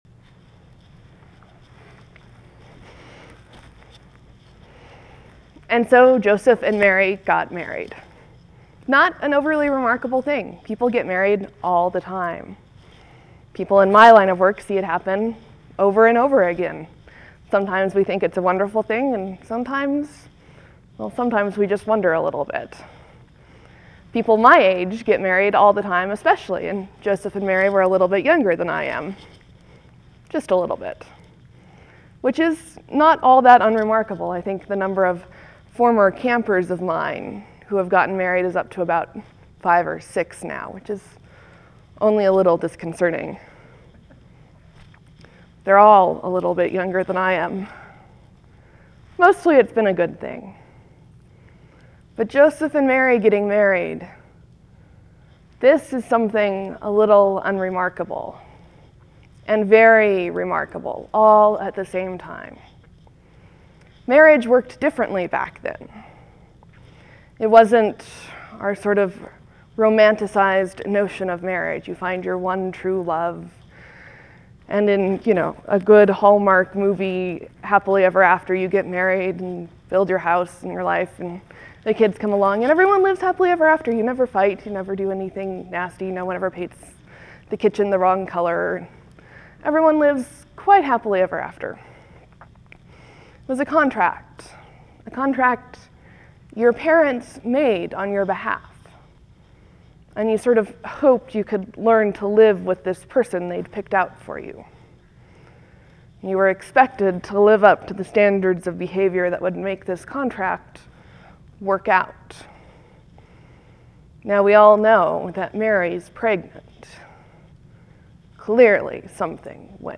(There are a few seconds of quiet before the sermon starts.